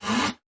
in_copingsaw_stroke_05_hpx
Coping saw cuts various pieces of wood. Tools, Hand Wood, Sawing Saw, Coping